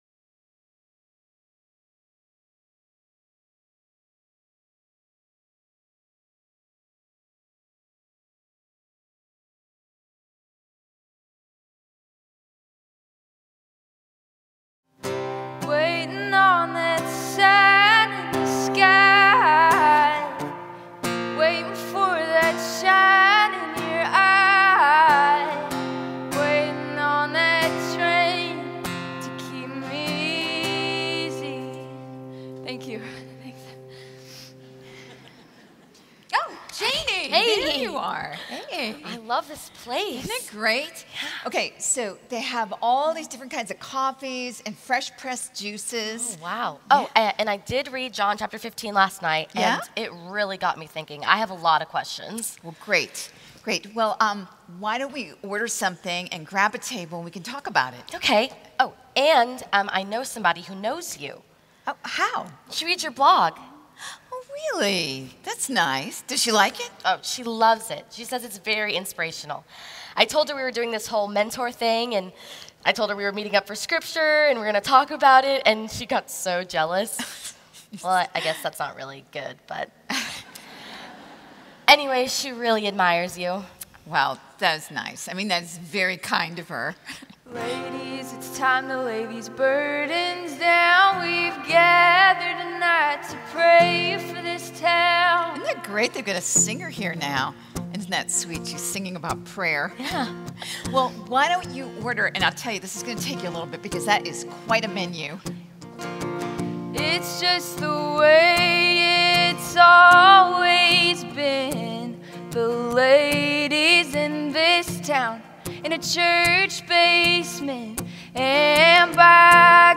Message 6 (Drama): You Don't Say